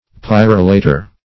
Pyrolator \Py*rol"a*tor\, n.